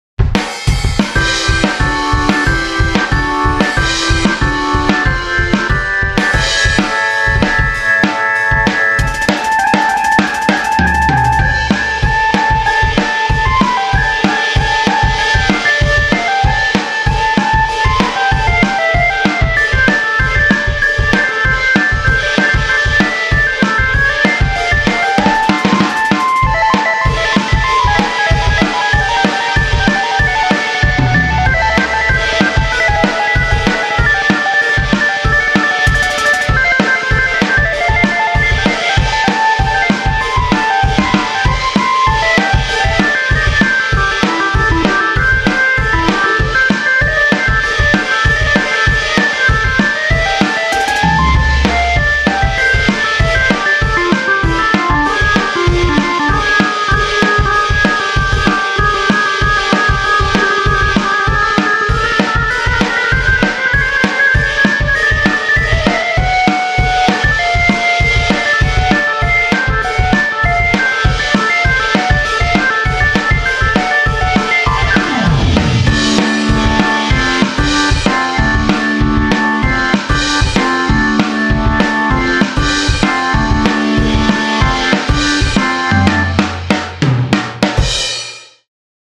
●DrawberOrgan